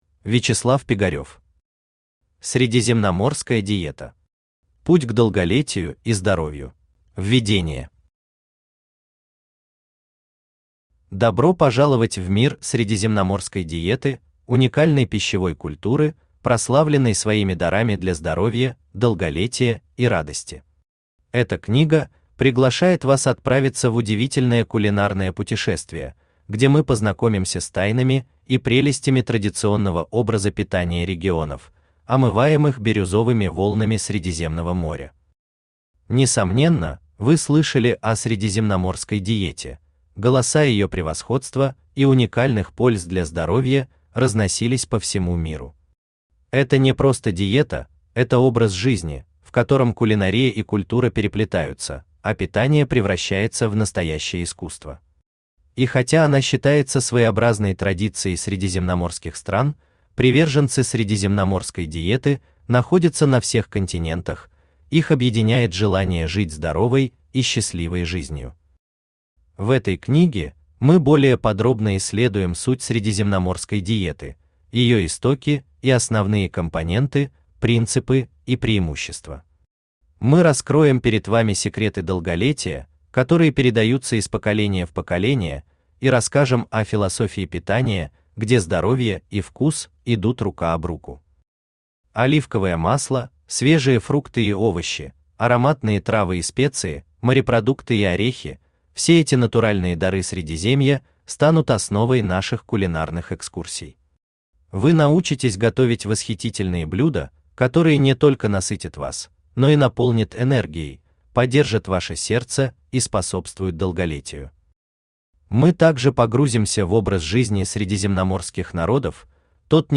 Аудиокнига Средиземноморская диета. Путь к долголетию и здоровью | Библиотека аудиокниг
Путь к долголетию и здоровью Автор Вячеслав Пигарев Читает аудиокнигу Авточтец ЛитРес.